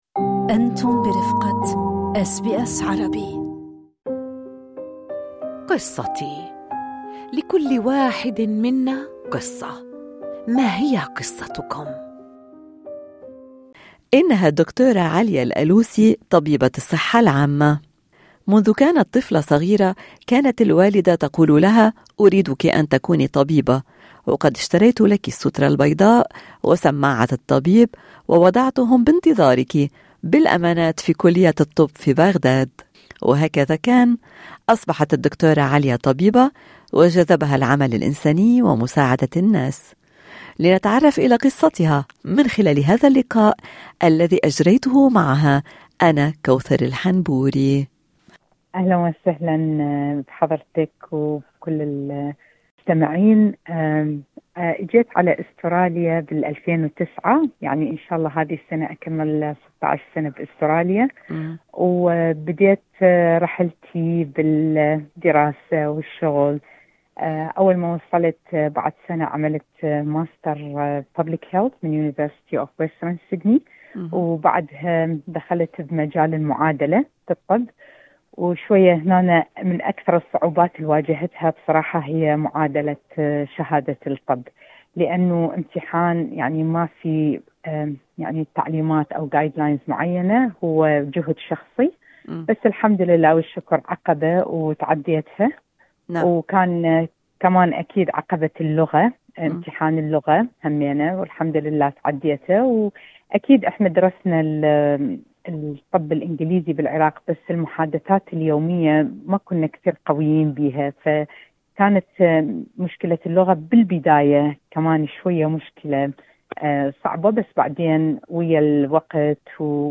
SBS Arabic 11:43 Arabic كيف تخطت الصعوبات في استراليا وما هي أجمل الذكريات التي حملتها معها من العراق: الإجابة ضمن المقابلة في الملف الصوتي أعلاه.